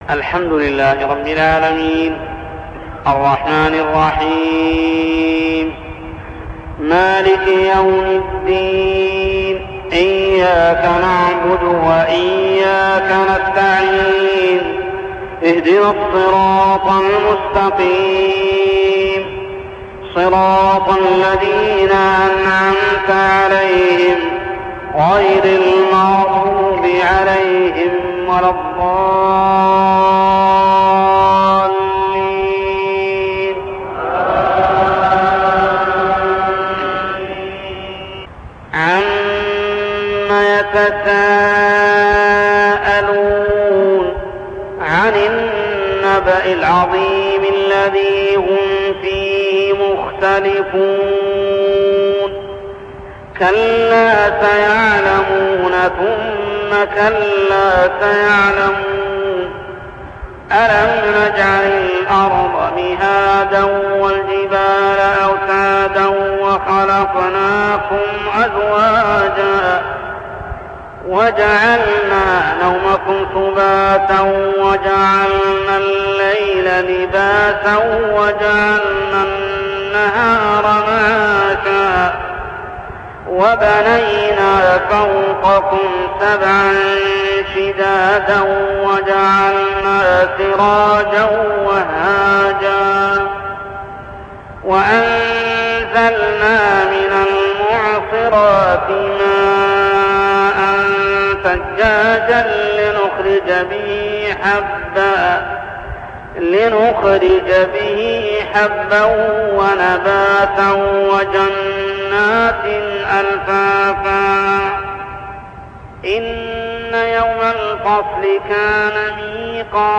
تلاوة من صلاة الفجر لسورة النبإ كاملة عام 1401هـ | Fajr prayer Surah Al-Naba > 1401 🕋 > الفروض - تلاوات الحرمين